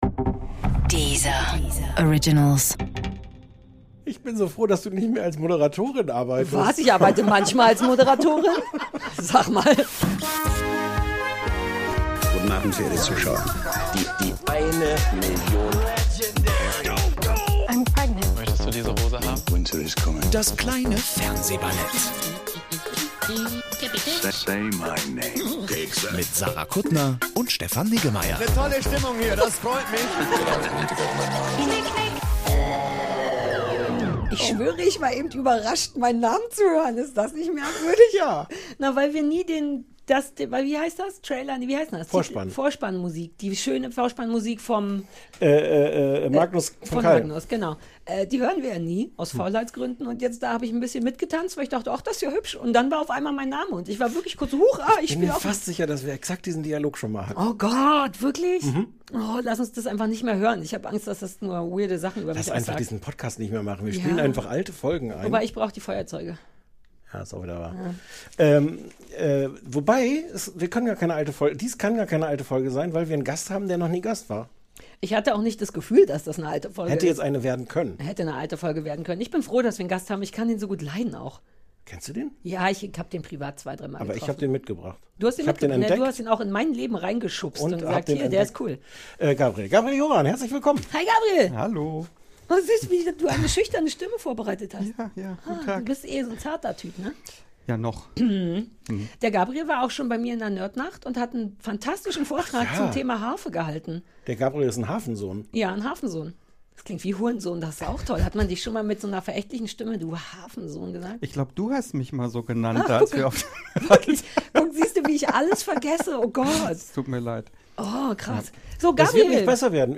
Mit ihm reden wir (teilweise mit vollem Mund) fast ausschließlich über Essens-Sendungen!